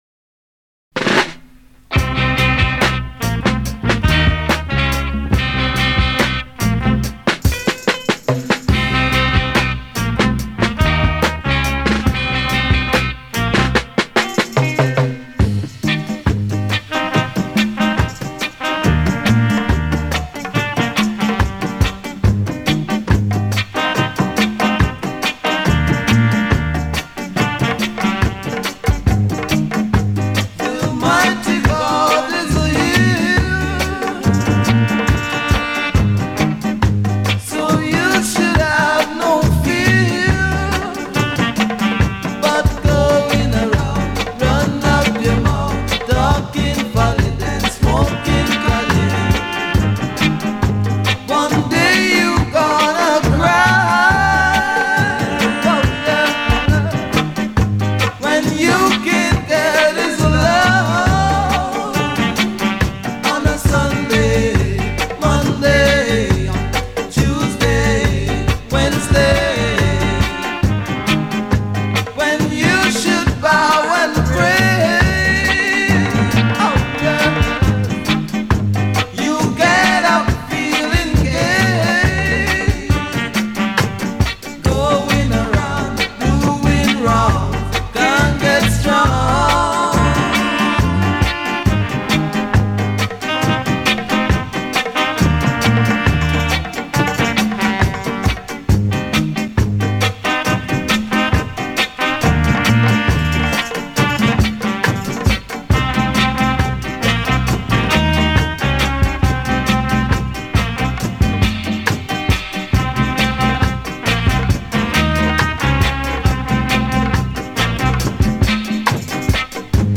Brooklyn band